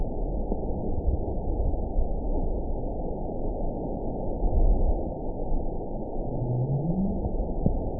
event 920233 date 03/07/24 time 22:52:35 GMT (1 year, 9 months ago) score 9.44 location TSS-AB04 detected by nrw target species NRW annotations +NRW Spectrogram: Frequency (kHz) vs. Time (s) audio not available .wav